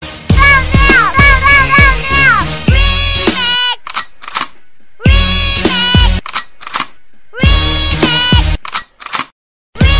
Tonos Divertidos